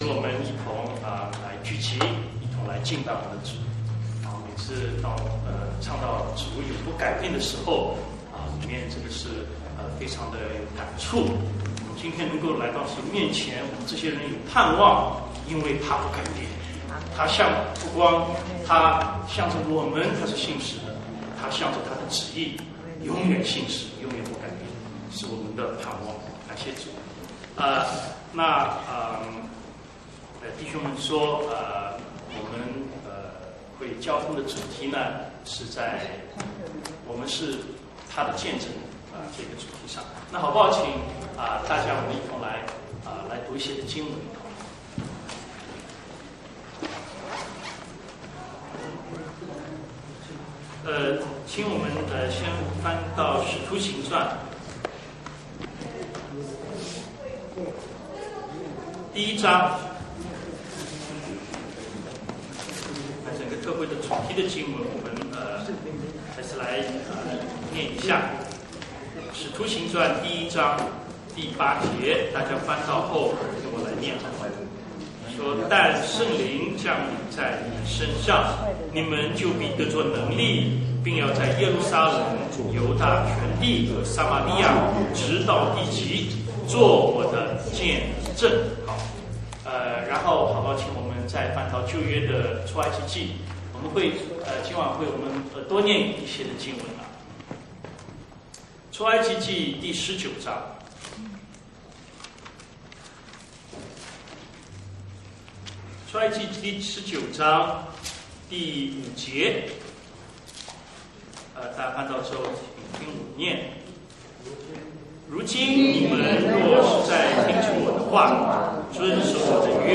东区基督教会特会讲道信息